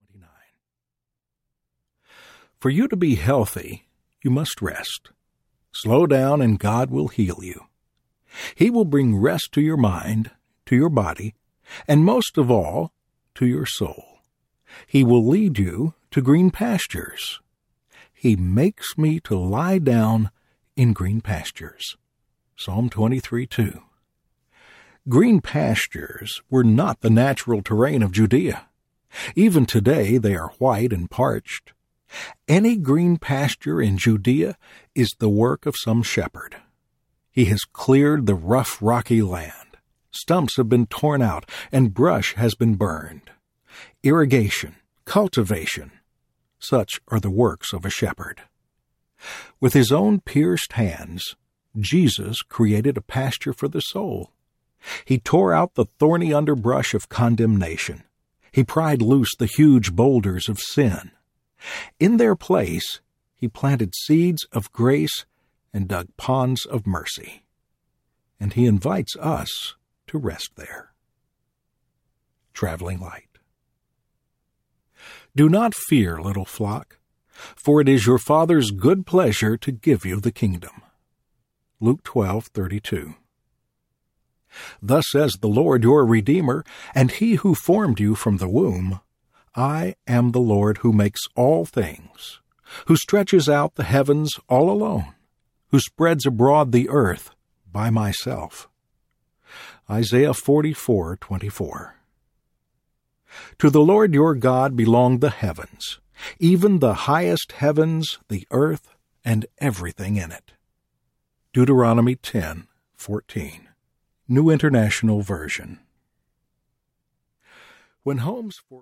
Fear Not Promise Book Audiobook
4.0 Hrs. – Unabridged